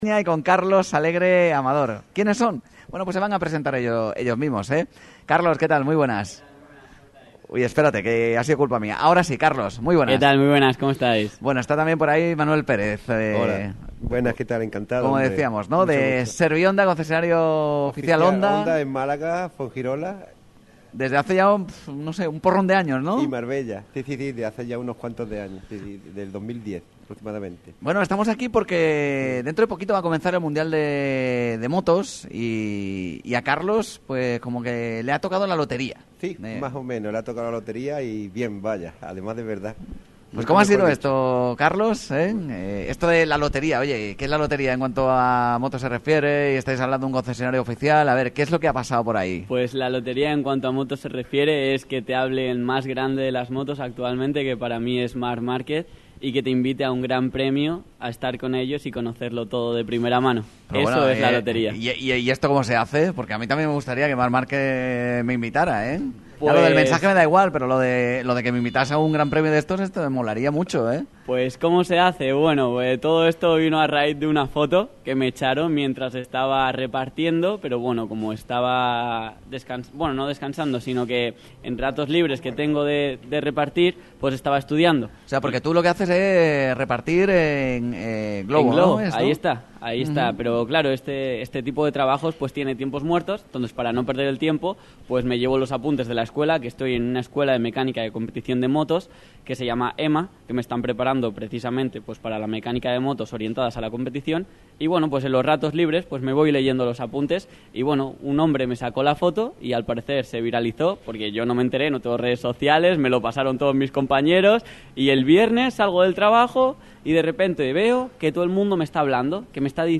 desde el restaurante Casa Antonio